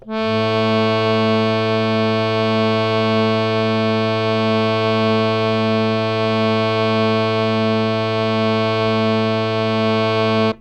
harmonium
A2.wav